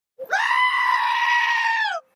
Goat Scream Meme Sound Effect sound effects free download